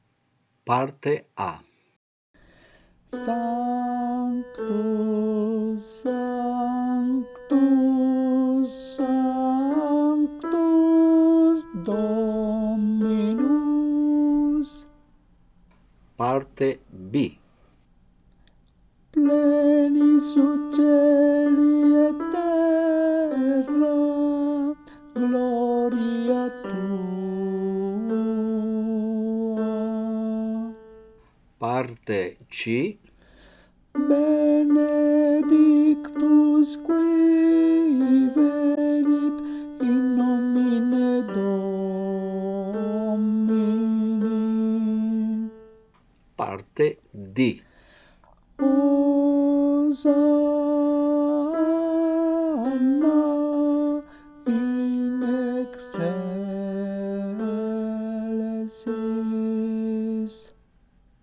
Parte tenori